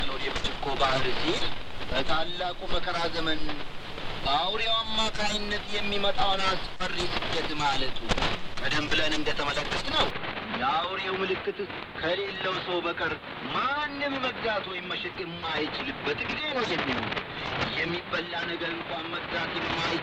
Reception comparisons
Second 10 - 20: Icom IC-R75 Kiwa Mod.